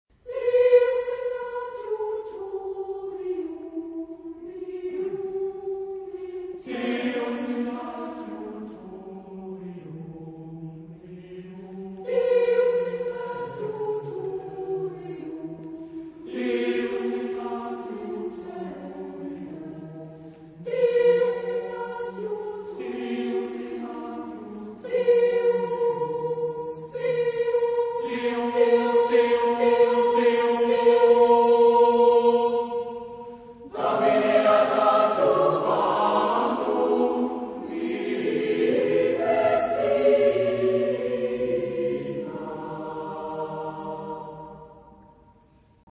Género/Estilo/Forma: música de escena ; Motete ; Sagrado
Tipo de formación coral: SATB  (4 voces Coro mixto )
Tonalidad : mi menor
Ref. discográfica: 7. Deutscher Chorwettbewerb 2006 Kiel